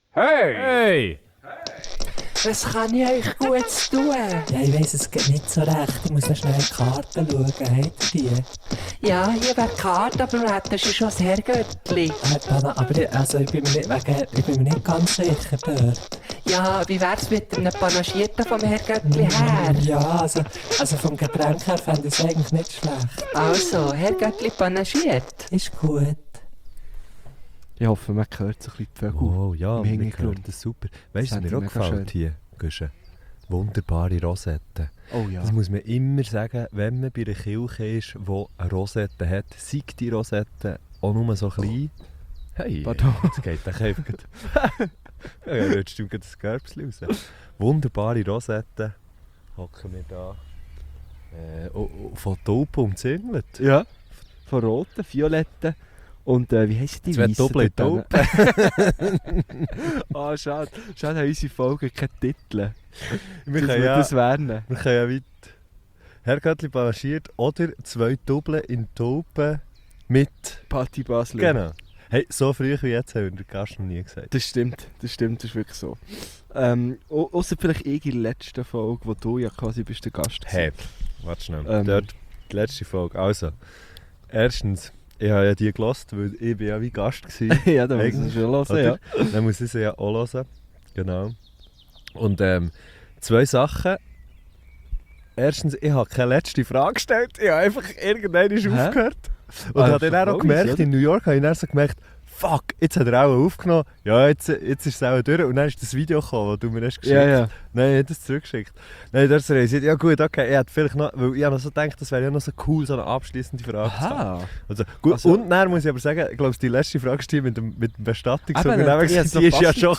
Wir sind in Bern. Und zwar auf der kleinen Schanze (die ja eigentlich auch gar nicht mal so klein ist wenn man sich das mal so überlegt (also für eine Schanze ist das eine ziemlich gäbige Grösse)). Genau: Wir nehmen draussen auf. Im Grünen.